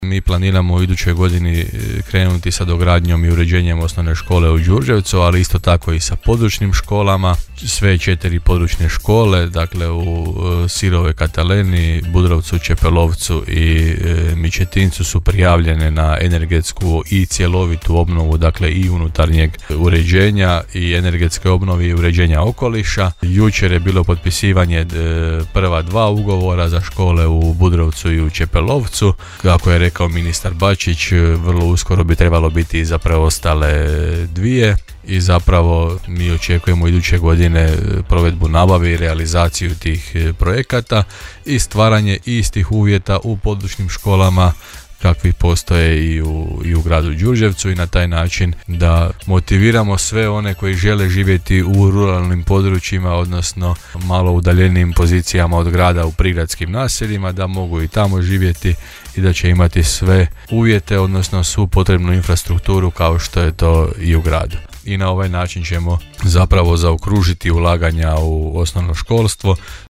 -rekao je gradonačelnik Janči u emisiji Gradske teme u programu Podravskog radija.